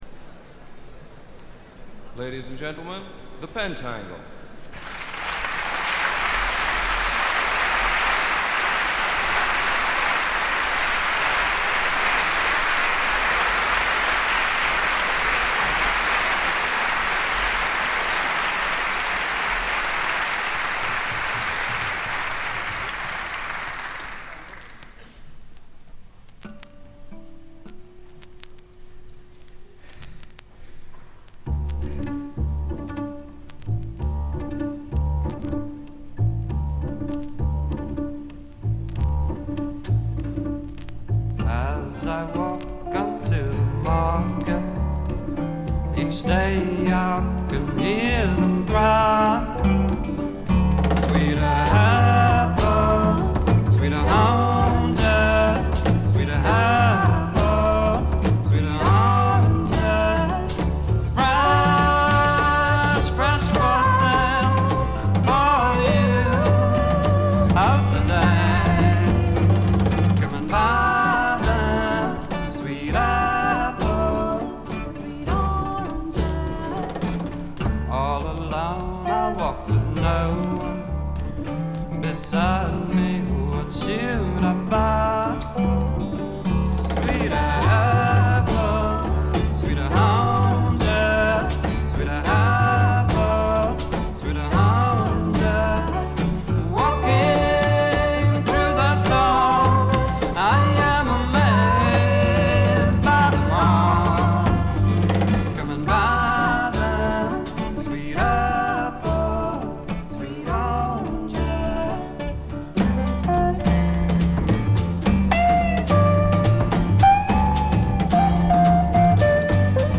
ホーム > レコード：英国 フォーク / トラッド
試聴曲は現品からの取り込み音源です。